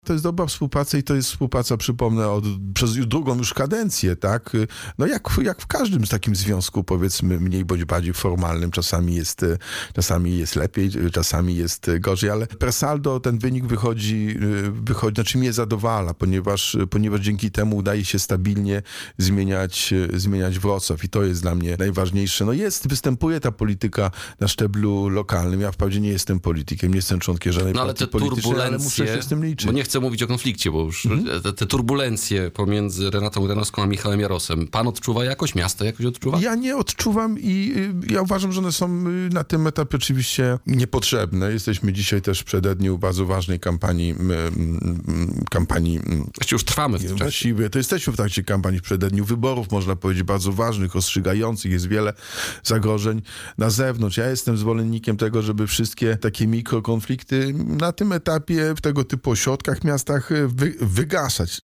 – Staram się szukać przebaczenia i wybaczać nawet tym, którzy czynią mi źle – powiedział w rozmowie z Radiem Rodzina prezydent Wrocławia, Jacek Sutryk.